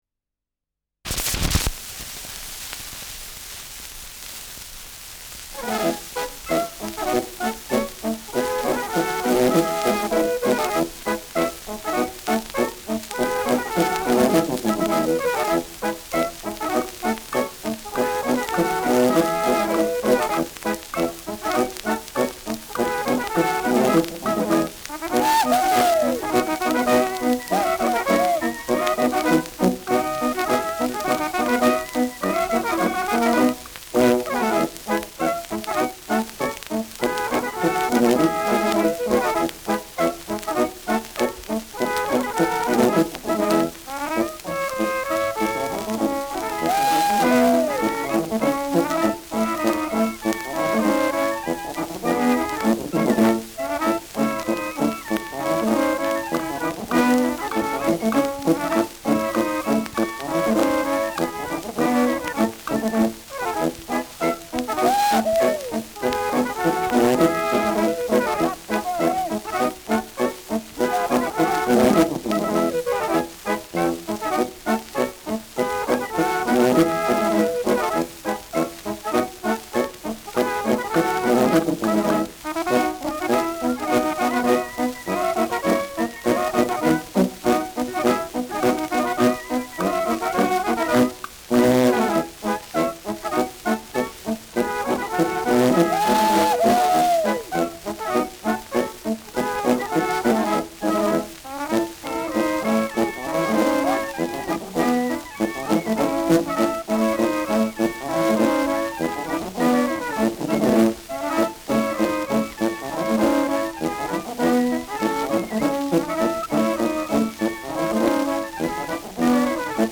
Schellackplatte
präsentes Rauschen
Mit Klopfgeräuschen (Schlagwerk).